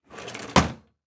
Drawer Close Sound
household